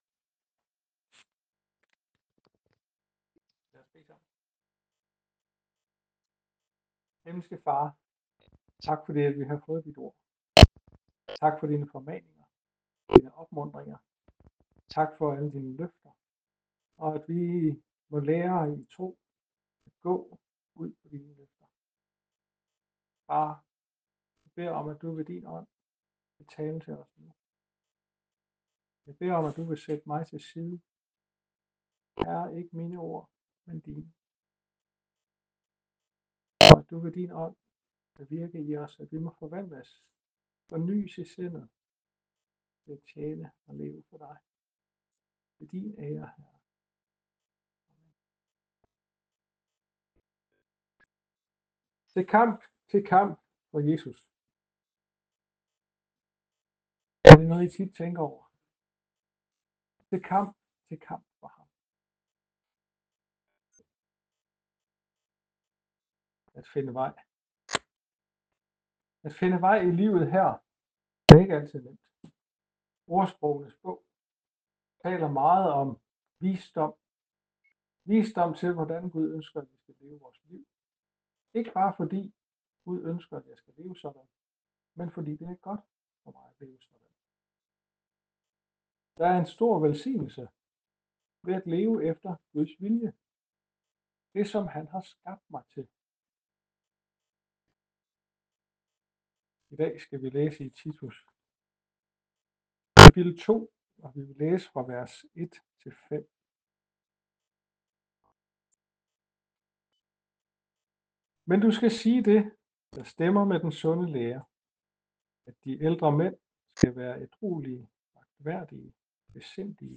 Taler